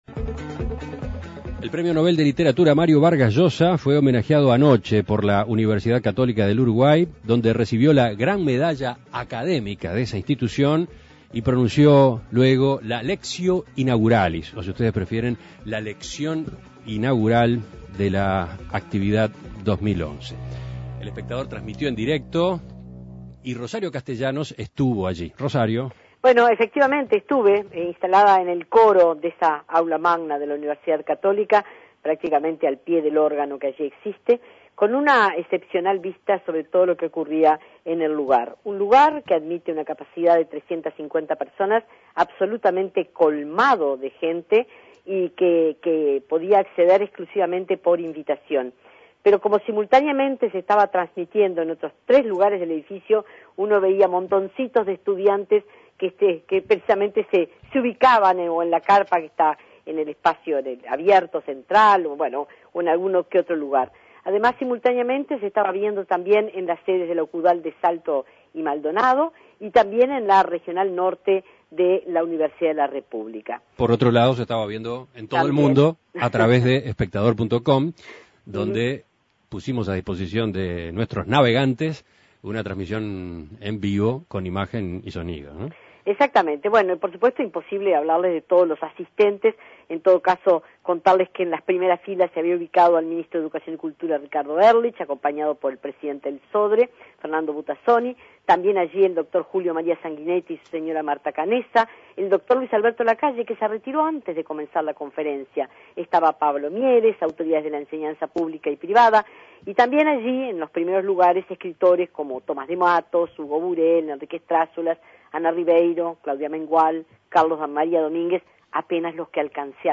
Conferencia de Mario Vargas Llosa en la Universidad Católica